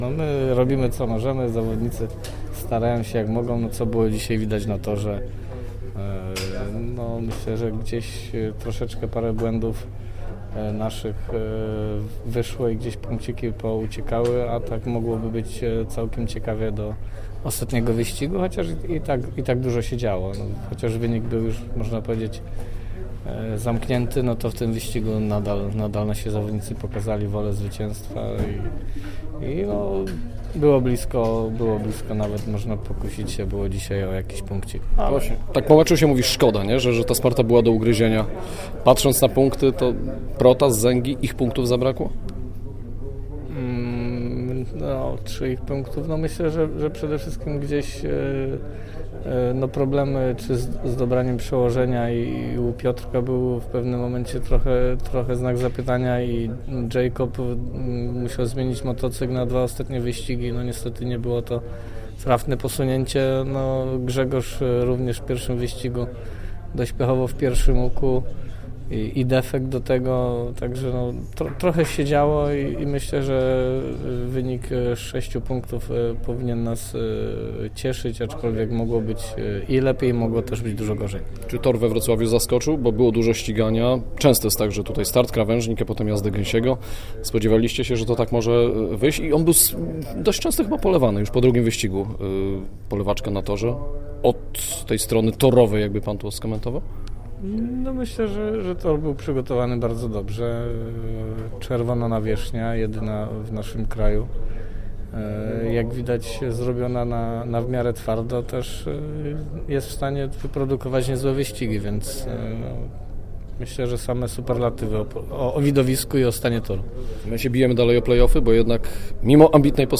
Fragment naszej rozmowy